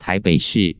使用說明 您輸入一個以上的中文字，多音字之發音以人工智慧技術判斷，結果僅供參考
::: 請輸入欲查詢漢字、注音或拼音（20字為限） 顯示聲調符號 不顯示聲調符號 查詢結果 查詢文字 臺北市 注音 ㄊㄞˊ 。 ㄅㄟˇ 。 ㄕˋ 漢語拼音 tái běi shì 通用拼音 tái běi shìh 注音二式 tái běi shr̀ 威妥瑪拼音 t'ai 2 pei 3 shih 4 耶魯拼音 tái běi shr̀ 發音 使用說明 您輸入一個以上的中文字，多音字之發音以人工智慧技術判斷，結果僅供參考